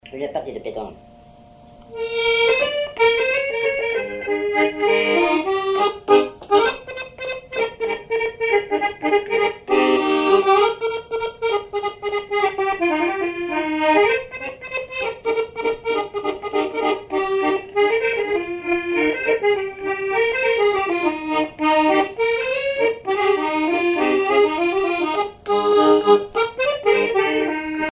accordéon(s), accordéoniste
danse : java
Répertoire à l'accordéon chromatique
Pièce musicale inédite